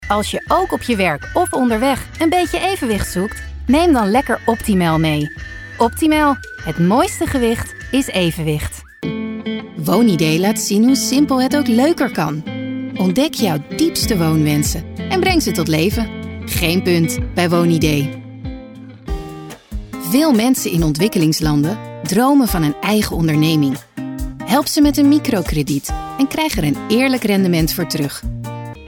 moedertaal: nederlands engels amerikaans vrouwenstem levering per e-mail mogelijk ervaring:gevorderde
klankleeftijd:klankleeftijd 25-40klankleeftijd 40-55
producties:nieuwspromo - commercialdocumentaire - bedrijfsfilmgesproken boek
Van laag en warm naar helder en opgeruimd, met een jong en fris of juist meer rijp en ervaren geluid.